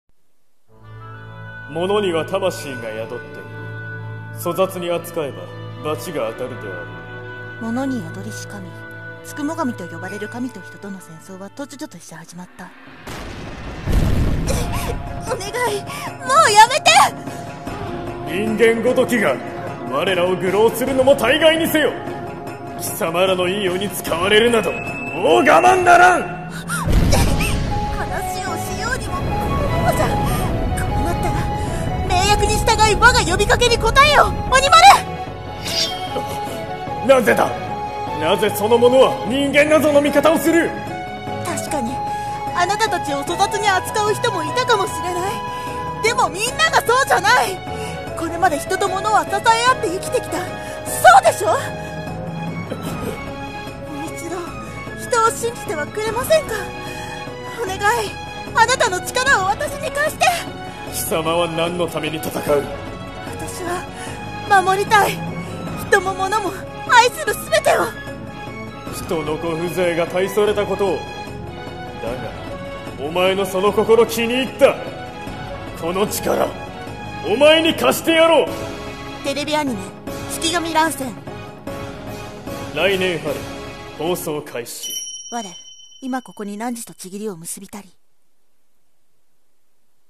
CM風声劇】憑神乱戦